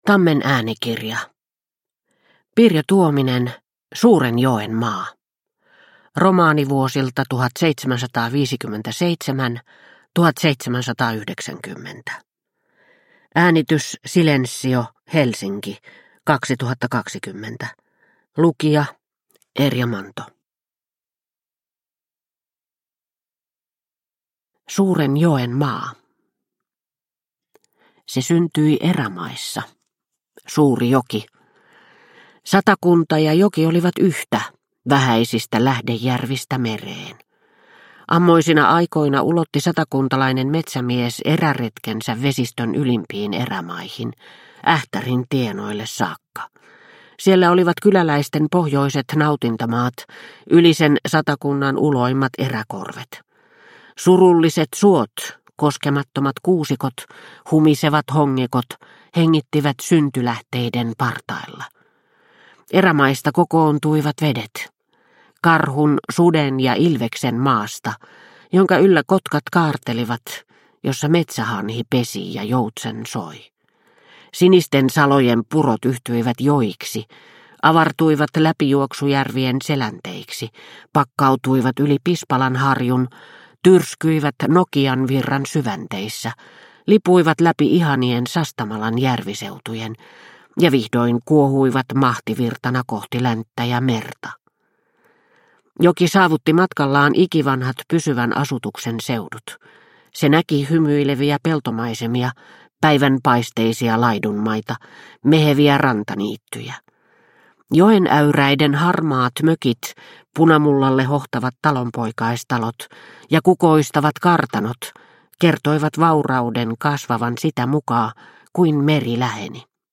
Suuren joen maa – Ljudbok – Laddas ner